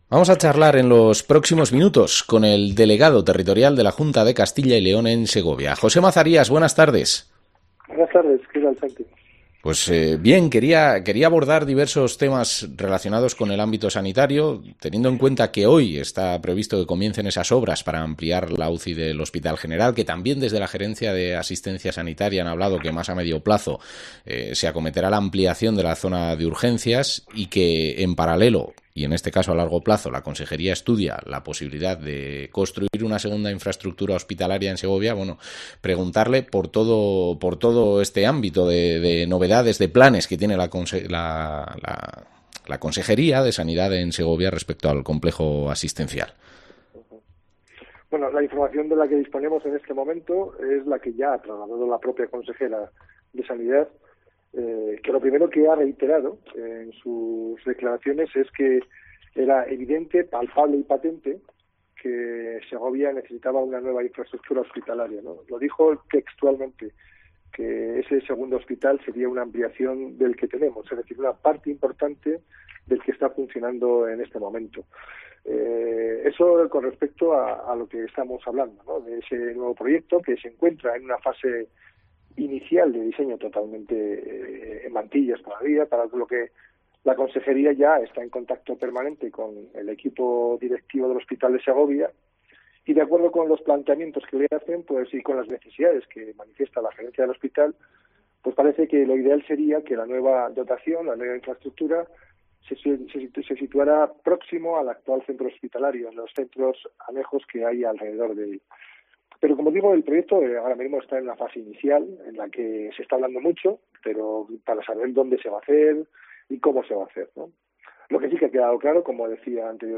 Entrevista al delegado territorial de la Junta, José Mazarías